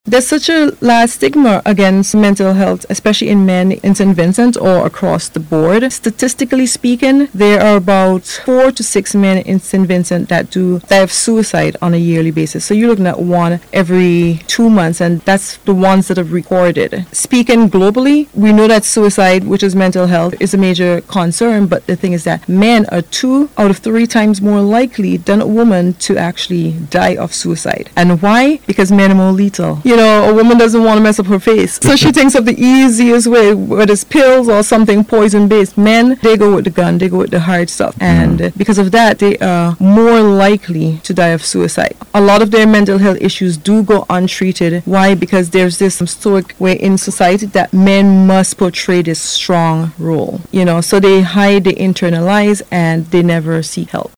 during an interview on NBC Radio.